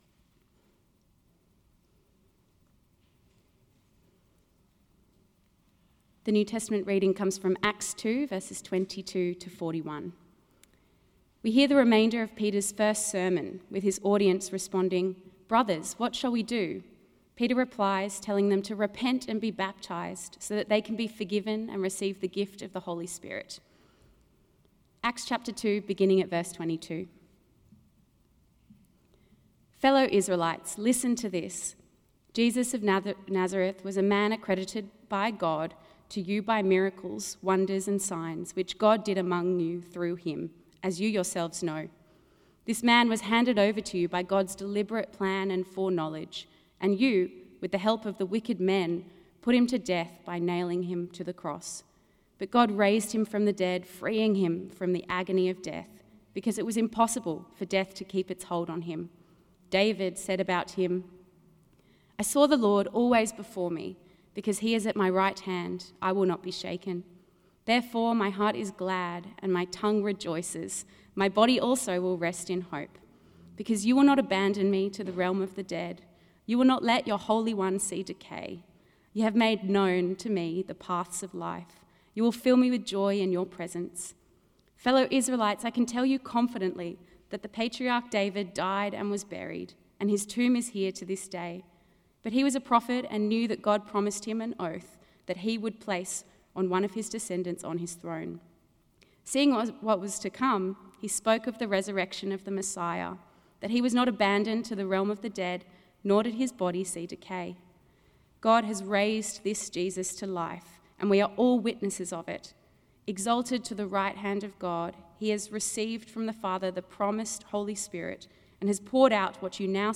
NEW Sermon 24th August .mp3